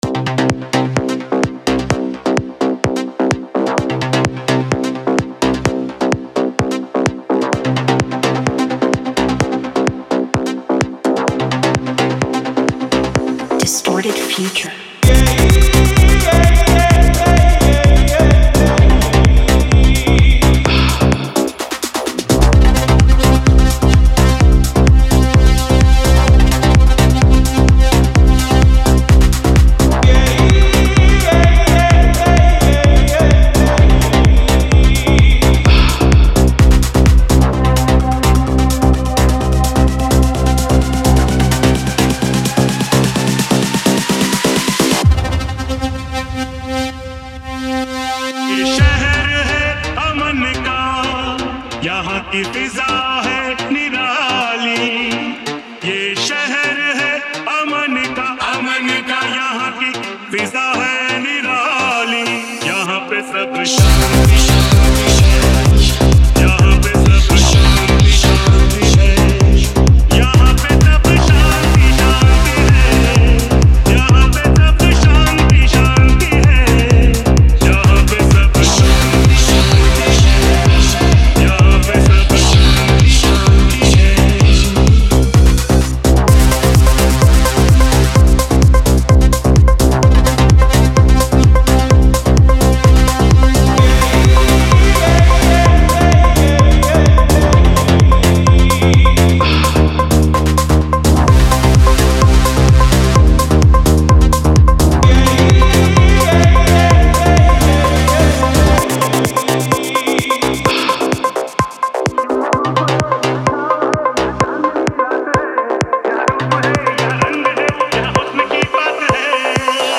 Retro Single Remixes